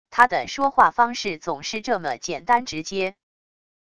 他的说话方式总是这么简单直接wav音频生成系统WAV Audio Player